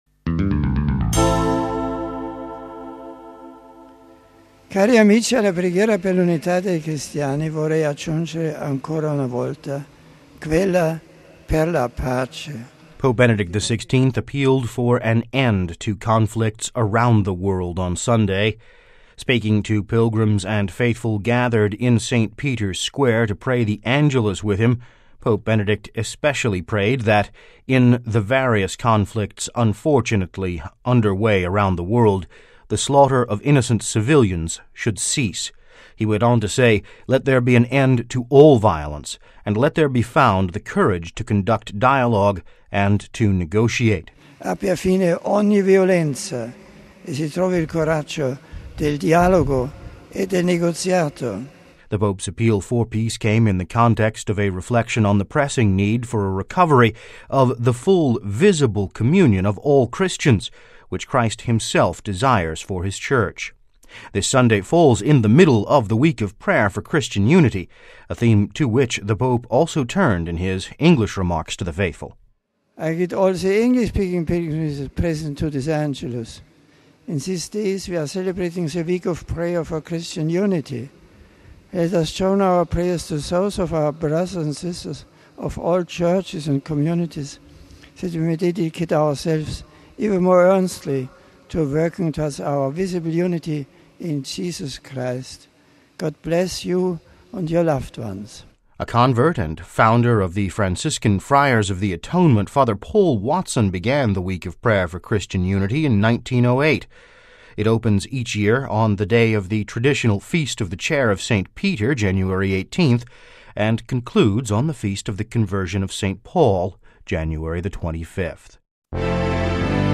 (Vatican Radio) Pope Benedict XVI appealed for an end to conflicts around the world on Sunday. Speaking to pilgrims and faithful gathered in St Peter’s Square to pray the Angelus with him, Pope Benedict especially prayed that, in the various conflicts unfortunately under way around the world, “The slaughter of innocent civilians should cease.”
This Sunday falls in the middle of the Week of Prayer for Christian Unity – a theme to which the Pope also turned in his English remarks to the faithful.